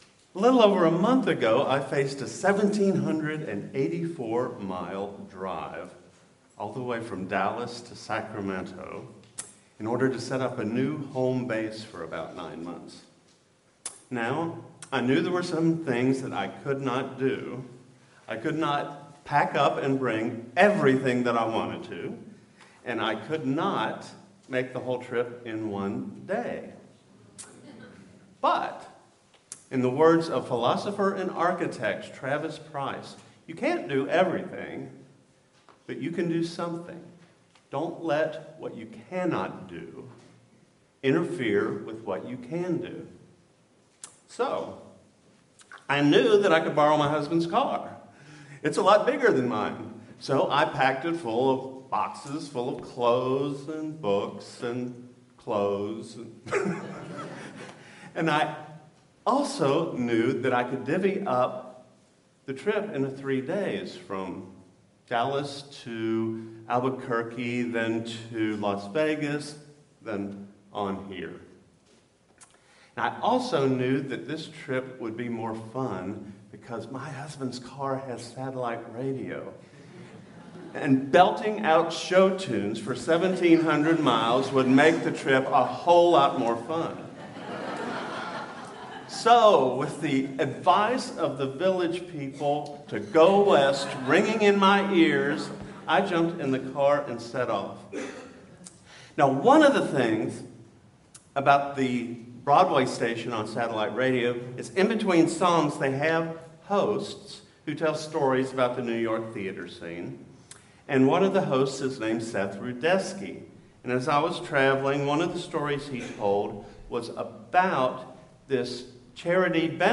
Sermon-When-I-Think-of-Home.mp3